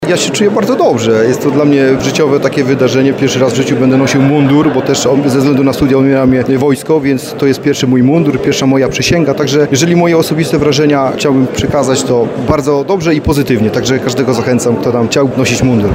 Ślubowanie odbyło się w Komendzie Wojewódzkiej Policji w Lublinie.